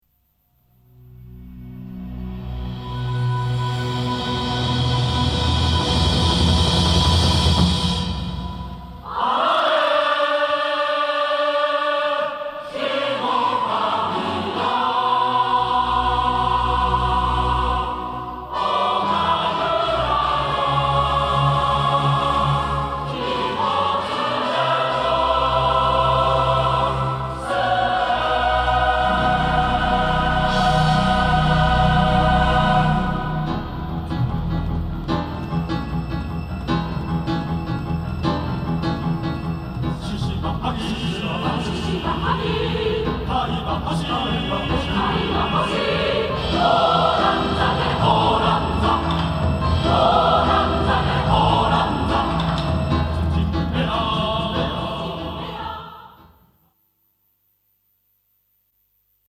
Chorus/Symphonic Works Reference CD